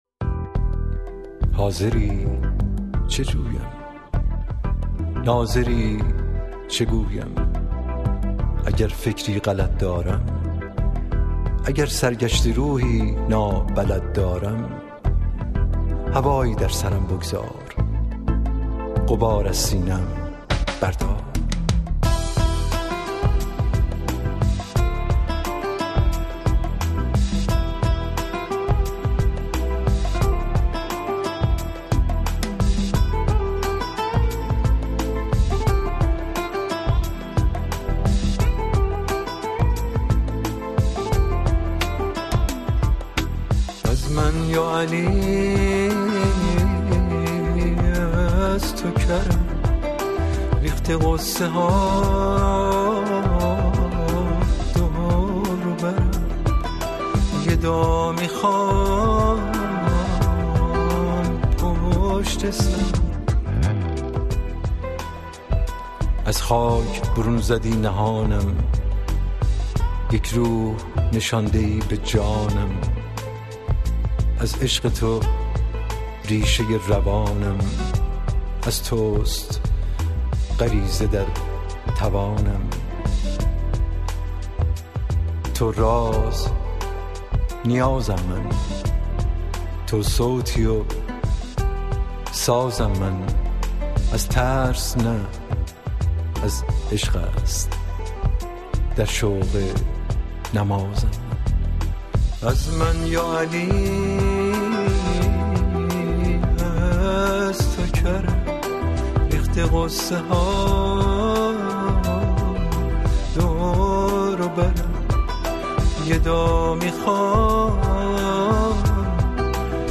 اطلاعات دکلمه